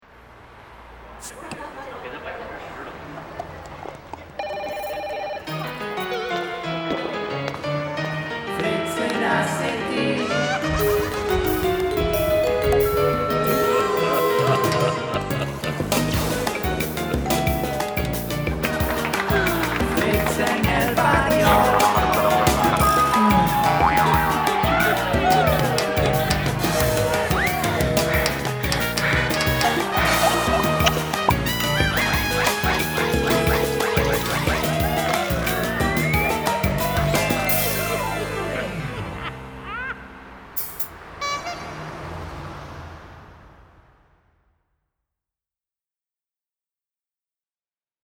Sprechprobe: Sonstiges (Muttersprache):
German voice over talent and musician